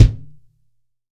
TUBEKICKT4-S.WAV